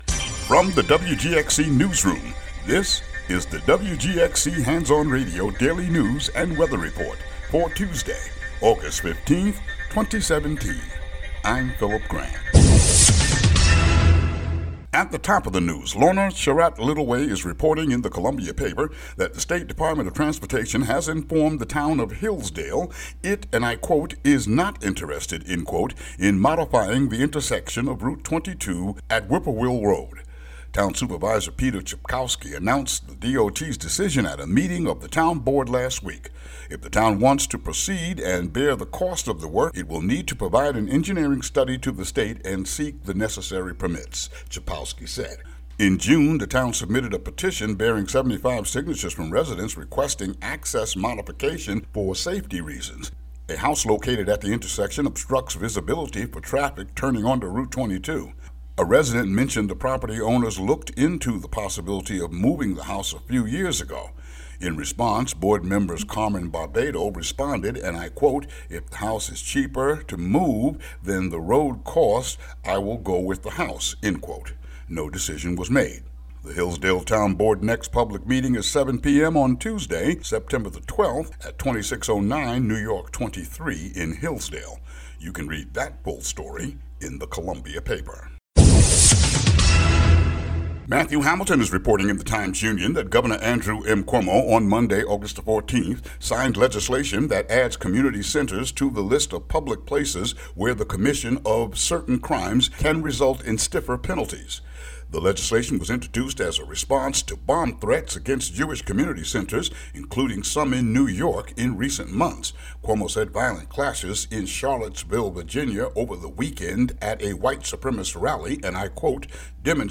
WGXC daily headlines for August 15, 2017.